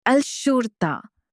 police-in-arabic.mp3